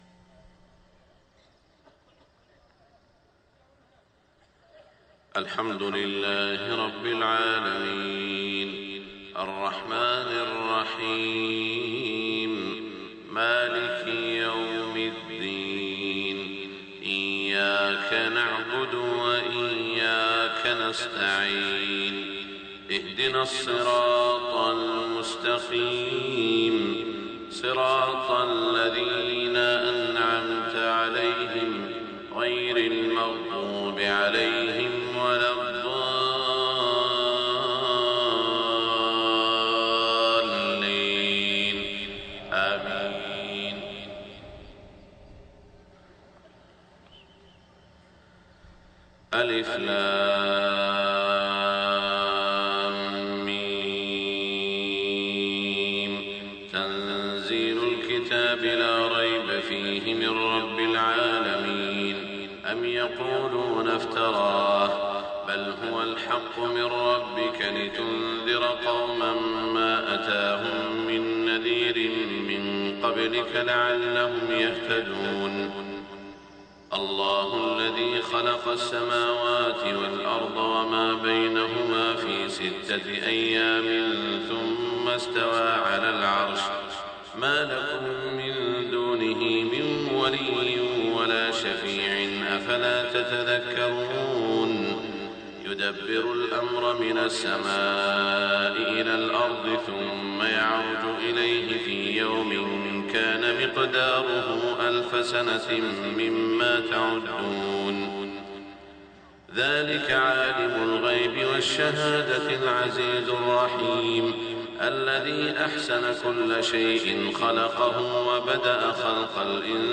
صلاة الفجر 10 ذو القعدة 1427هـ من سورتي السجدة و الانسان > 1427 🕋 > الفروض - تلاوات الحرمين